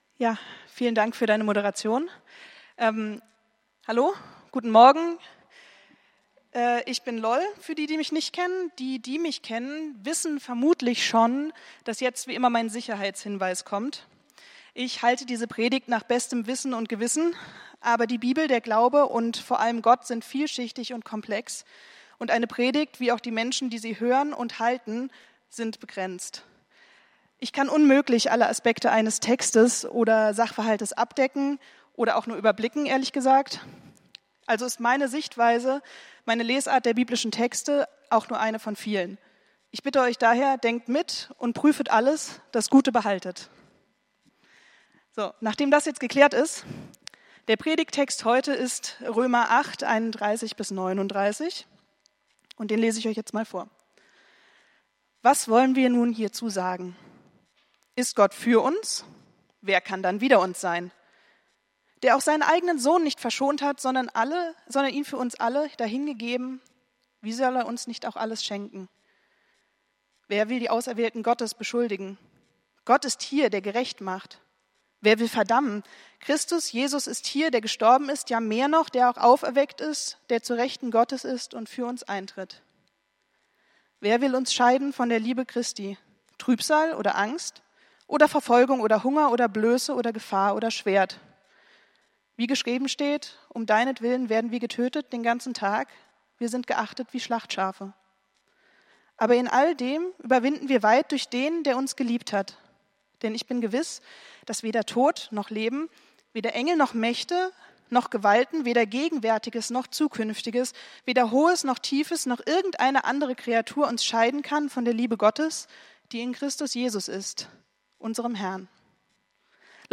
Predigt vom 27.08.2023